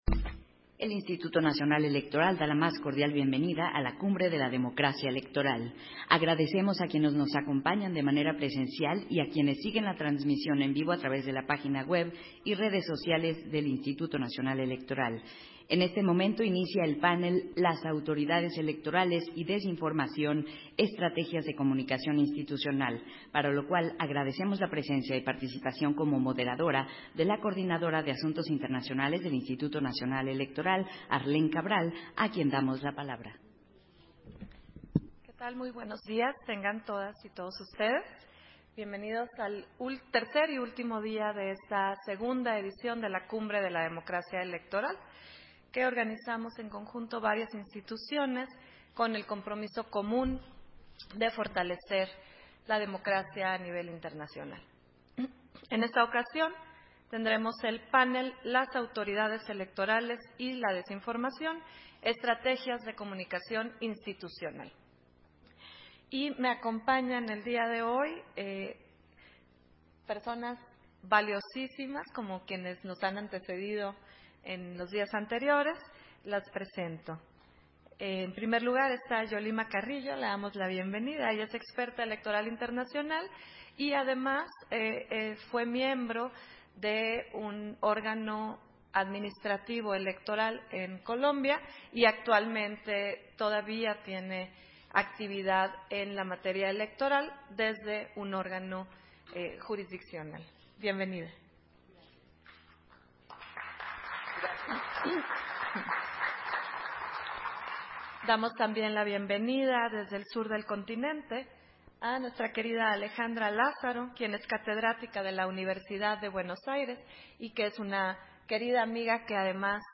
Versión estenográfica del panel, Las autoridades electorales y la desinformación: Estrategias de comunicación institucional, en el marco de la II Cumbre de la Democracia Electoral